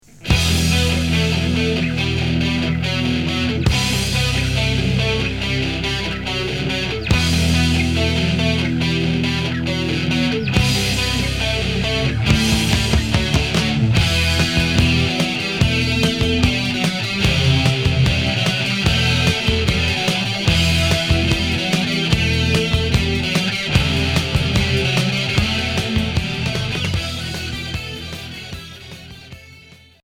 Hard heavy Unique 45t retour à l'accueil